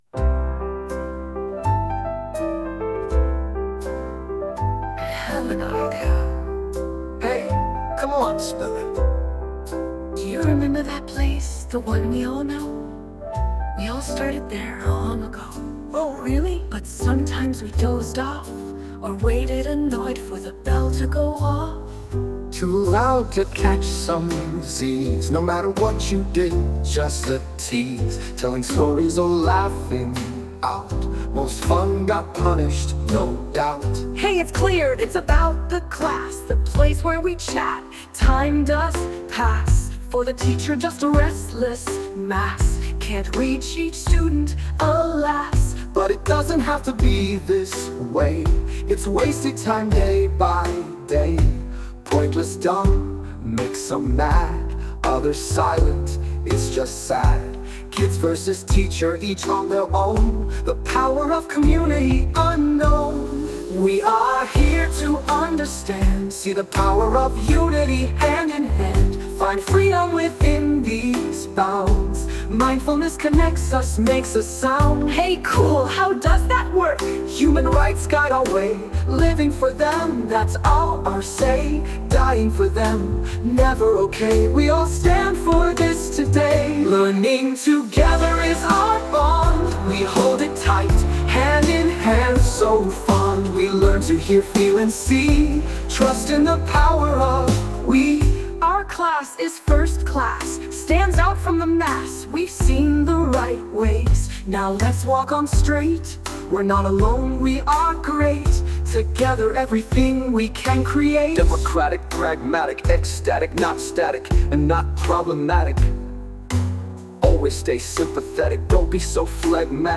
Slow JazzBlues